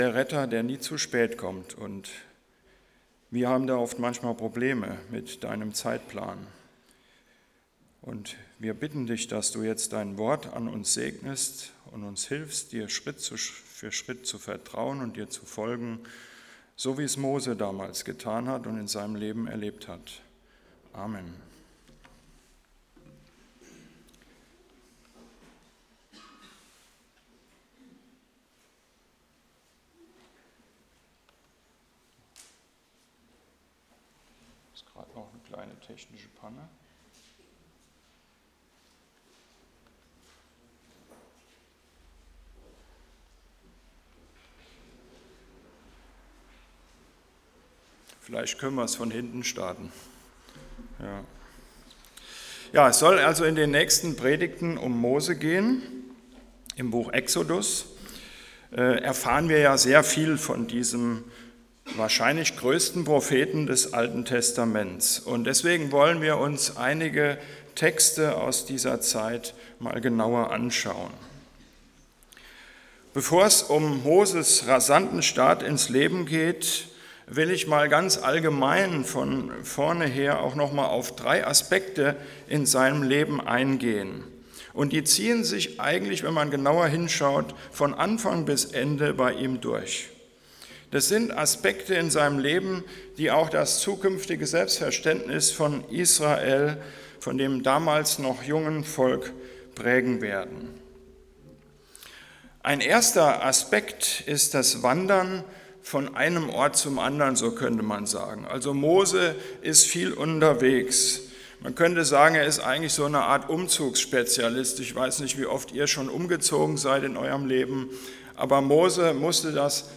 Predigtdetails anzeigen und abspielen